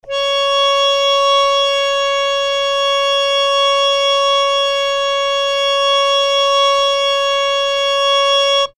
interactive-fretboard / samples / harmonium / Cs5.mp3